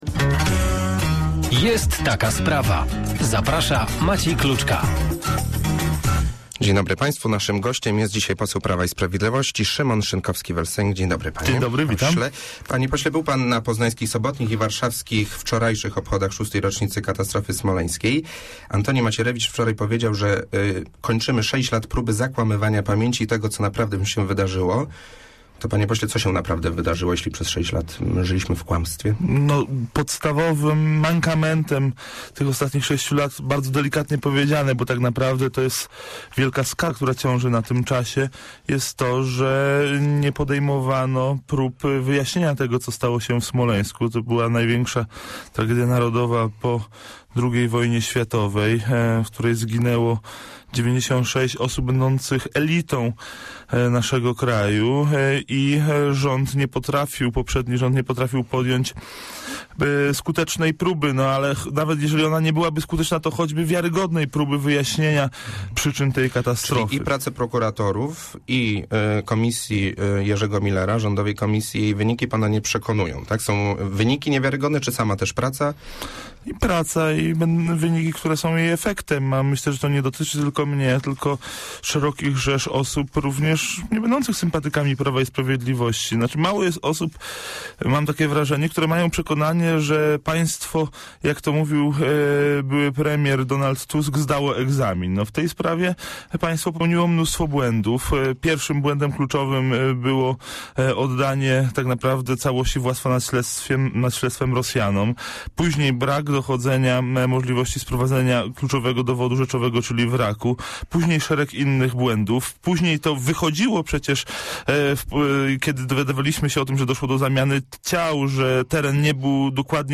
Poseł Prawa i Sprawiedliwości - Szymon Szynkowski vel Sęk - w porannej rozmowie Radia Merkury zaapelował o powściągliwość w formułowaniu tez o przebiegu katastrofy smoleńskiej.
dm62ual3nzht36a_rozmowa_szynkowski.mp3